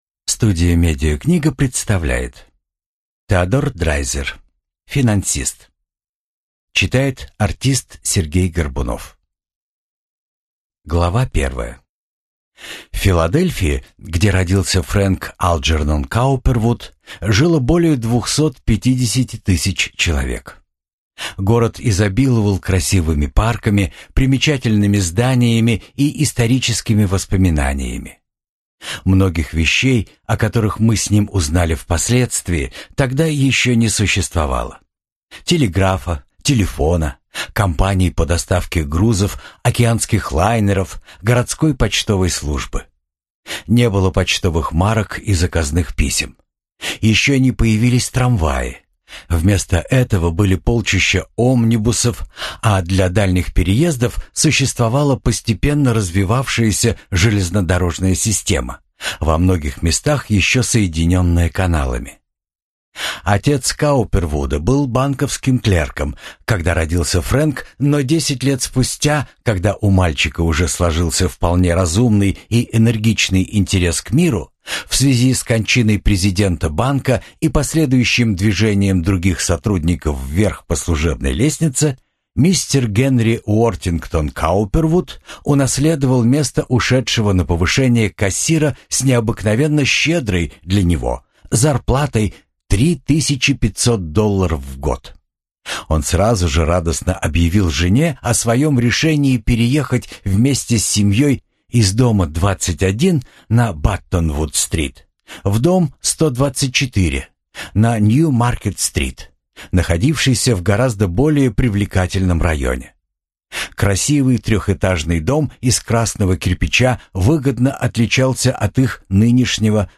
Аудиокнига Финансист | Библиотека аудиокниг